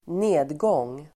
Uttal: [²n'e:dgång:]